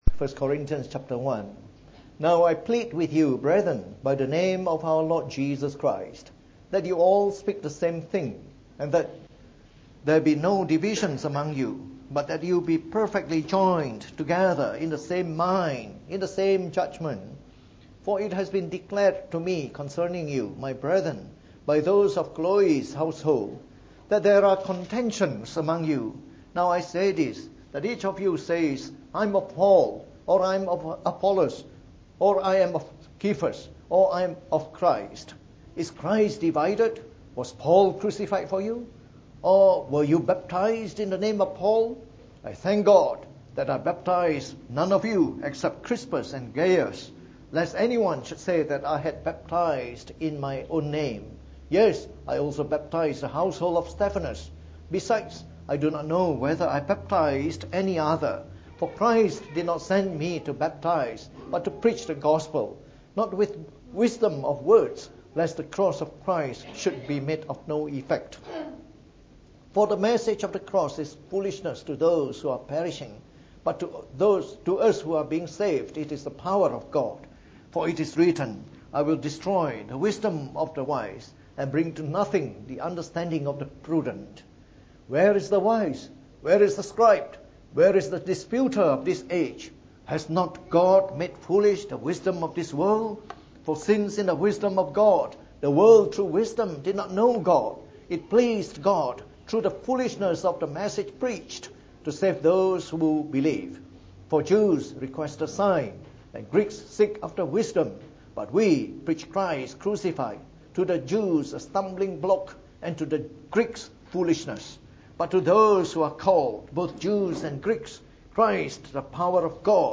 From our series on 1 Corinthians delivered in the Evening Service.